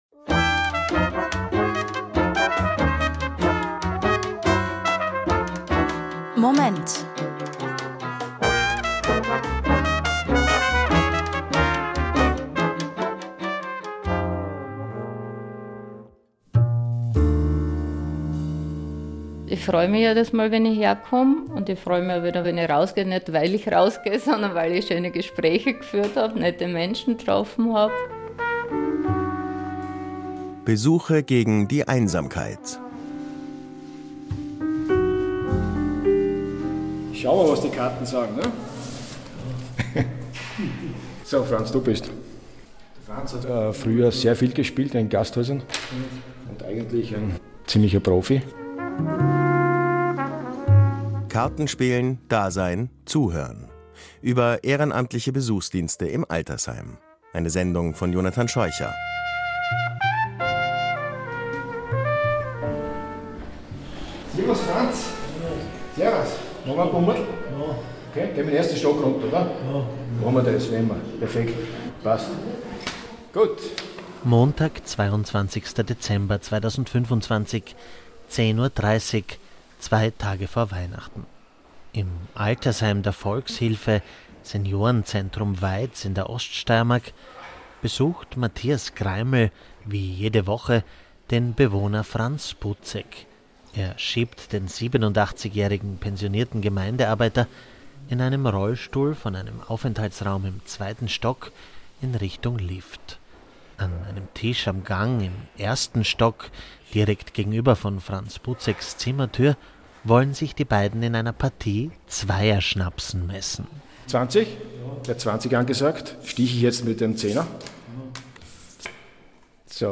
Ö1-Radiobeitrag über ehrenamtlichen Besuchsdienst